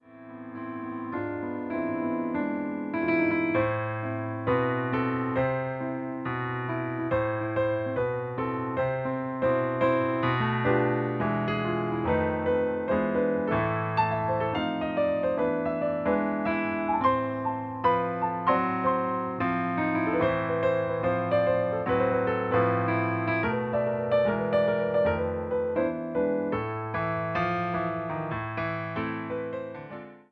Popular Jewish Music
This piano selection